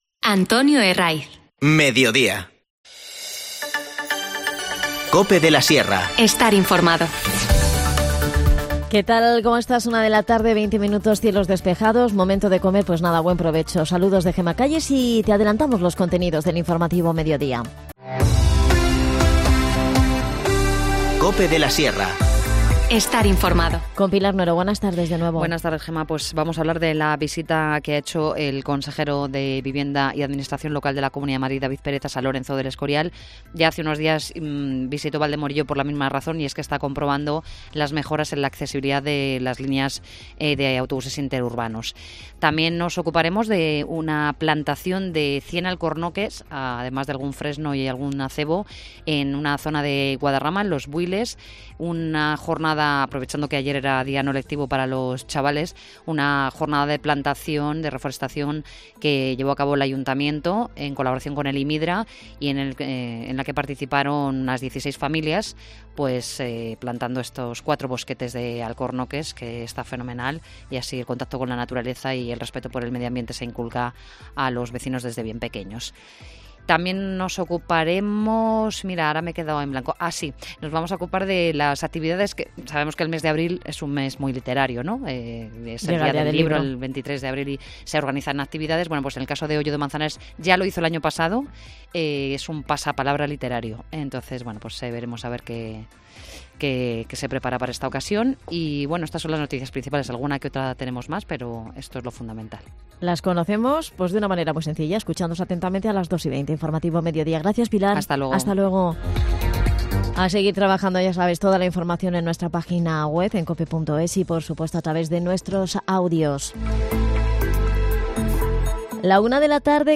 Si tienes una discapacidad y quieres descubrir nuevos horizontes profesionales, el servicio de orientación laboral Dinamiza Moral + INSERTA Empleo te ofrece recursos y servicios para que hagas realidad tu proyecto profesional. Virginia Ortiz, concejal de Empleo en Moralzarzal, nos cuenta en qué consiste y quienes pueden participar.